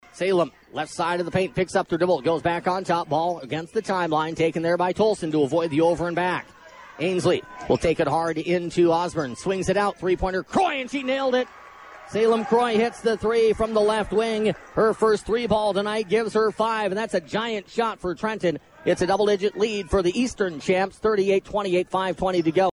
High School Basketball